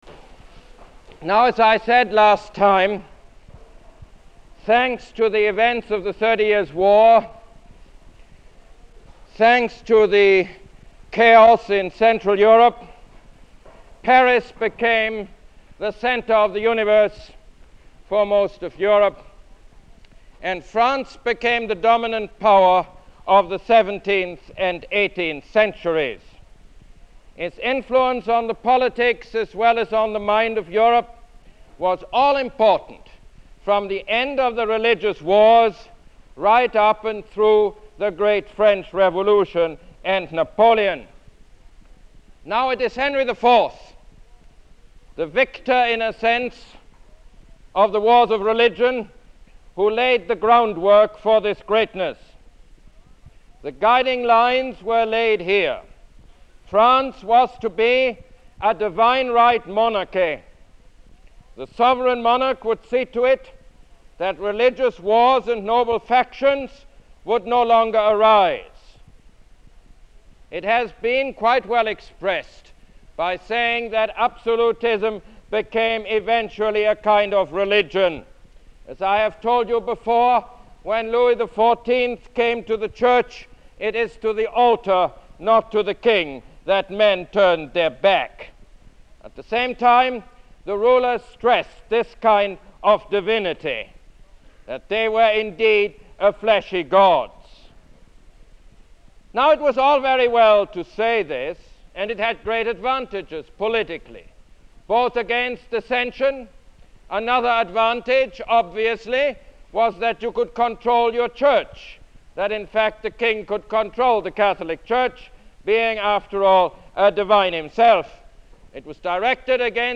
Lecture #12 - Louis XIV